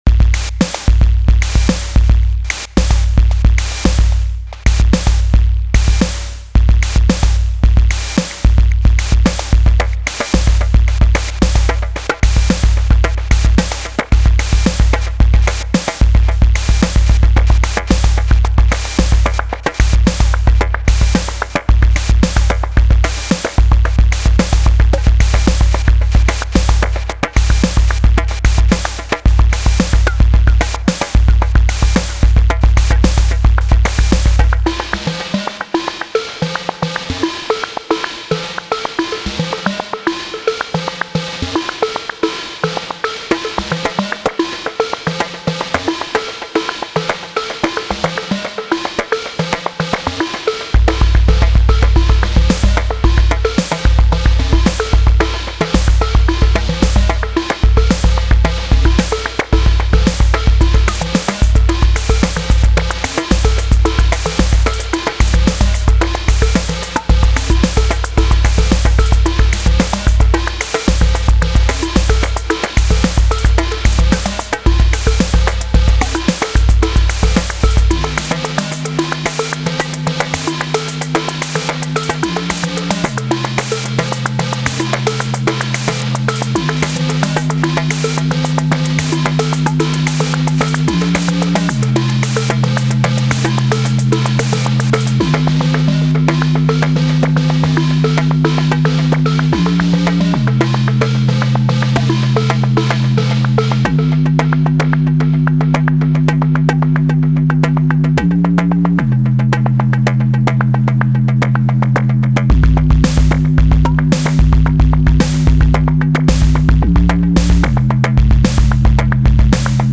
A more behaved and melodic beat. 5 tracks of SDB.